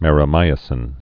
(mĕrə-mīə-sĭn)